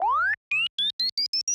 BEEPTimer_Anticipation Beeps_05.wav